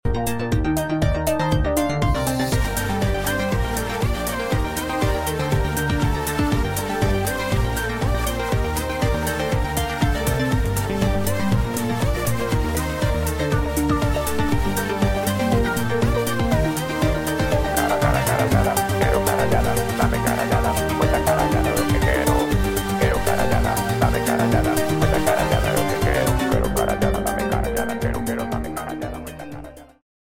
un tema para percusión e electrónica